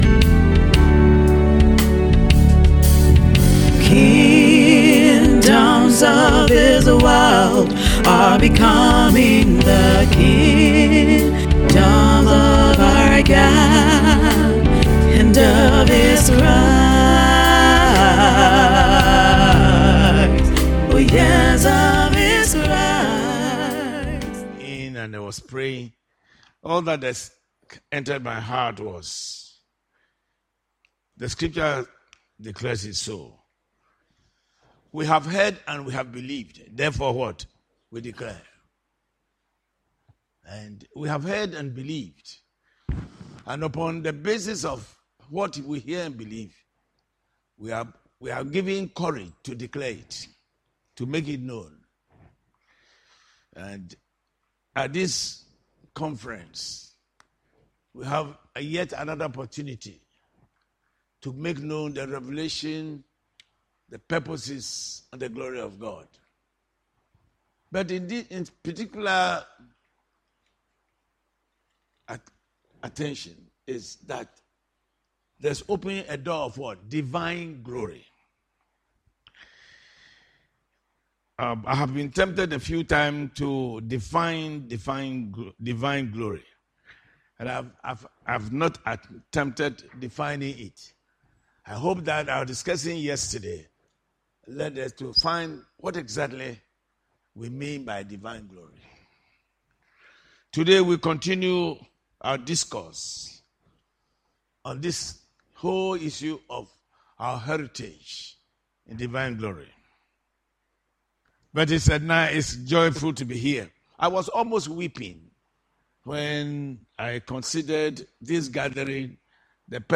January 19, 2026 CHRIST, THE HOLYSPIRT AND THE CHURCH IN MISSION Series: Audio Sermon SERMON INSIGHTS All that entered my heart was the scripture: what we have heard and we have believed, therefore we declare.